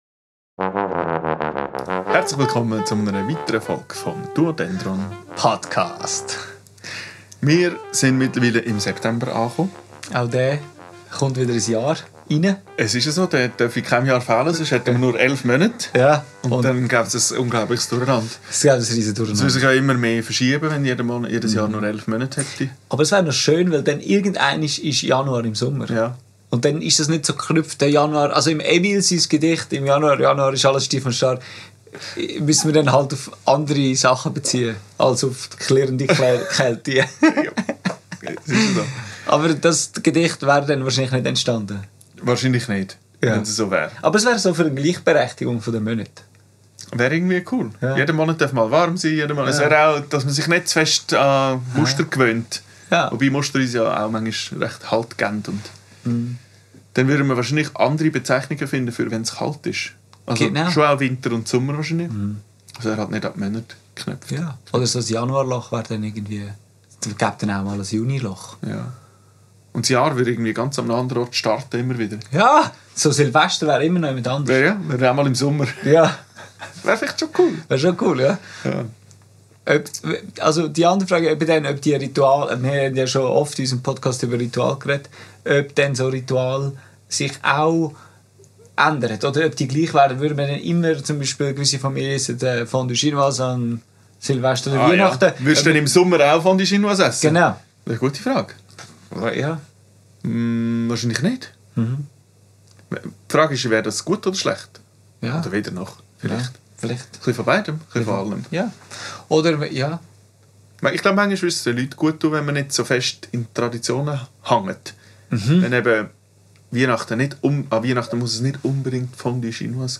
In unseren Augen eine Sternstunde des freien Zusammenspiels.
in der Wasserkirche Zürich